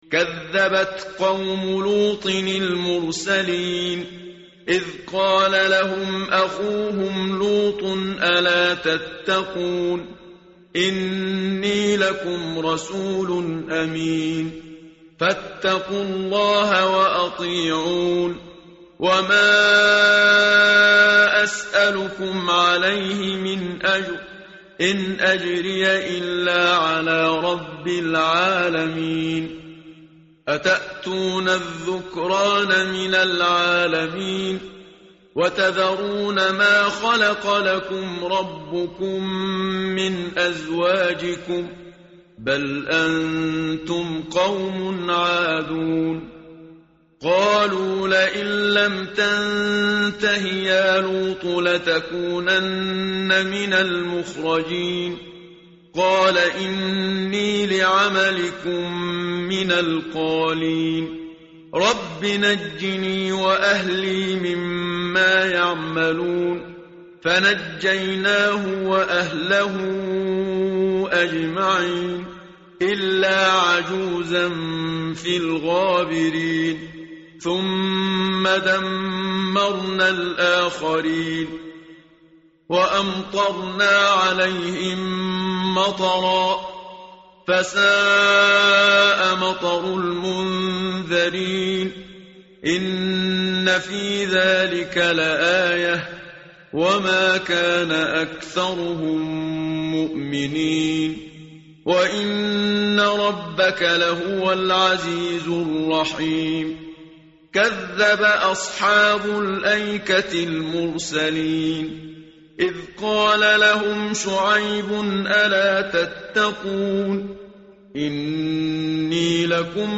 متن قرآن همراه باتلاوت قرآن و ترجمه
tartil_menshavi_page_374.mp3